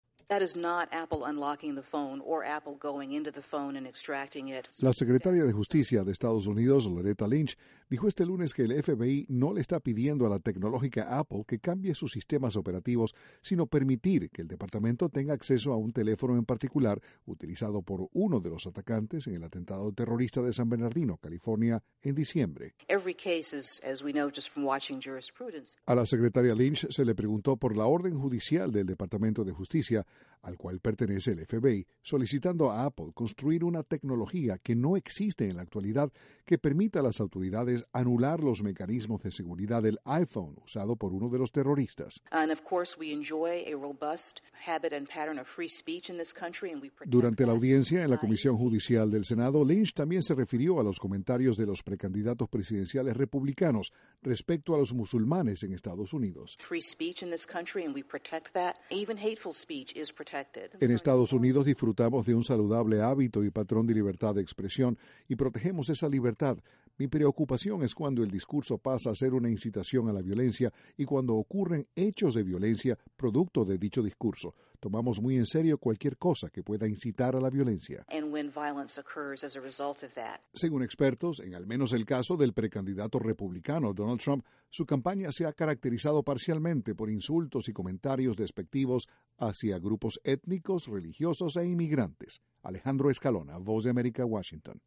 La Secretaria de Justicia de Estados Unidos se mostró preocupada por el lenguaje usado por algunos precandidatos en el ruedo electoral, y otros temas de actualidad. Desde la Voz de América, Washington, informa